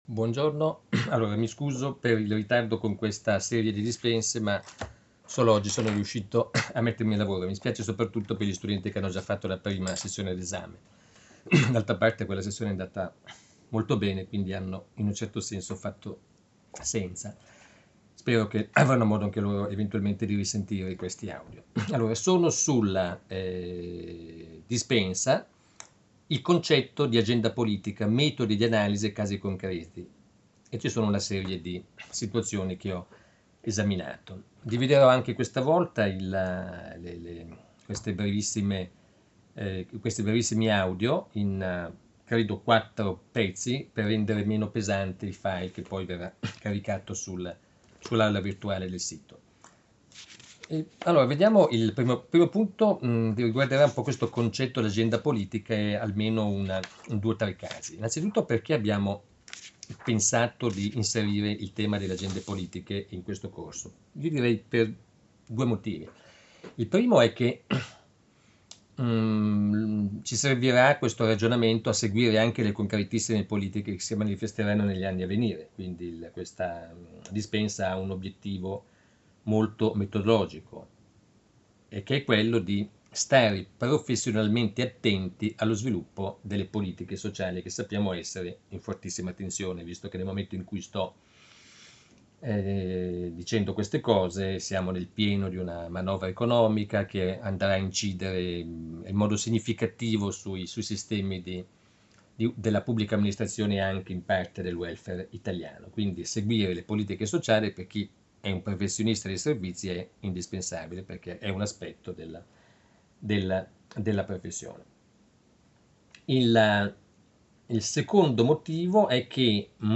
Il concetto di AGENDA POLITICA e Analisi di alcune politiche sociali. 4° Lezione